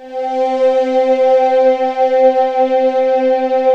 Index of /90_sSampleCDs/USB Soundscan vol.28 - Choir Acoustic & Synth [AKAI] 1CD/Partition D/09-VOCODING
VOCODINGC3-L.wav